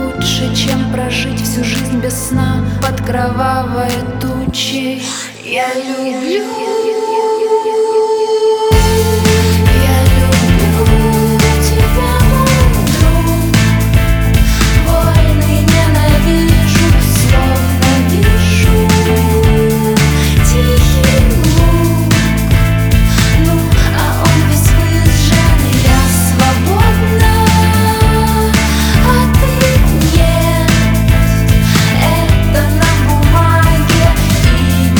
Жанр: Русская поп-музыка / Поп / Русский рок / Русские